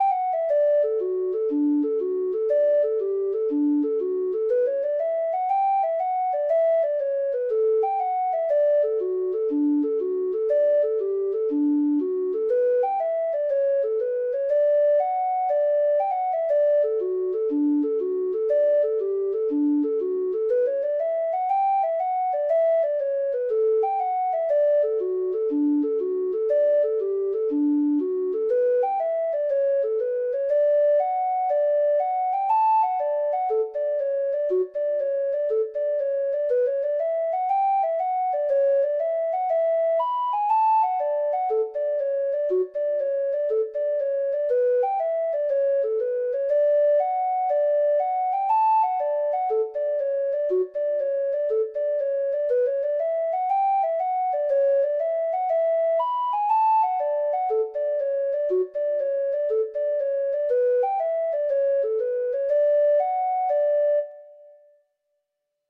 Free Sheet music for Treble Clef Instrument
Traditional Music of unknown author.
Irish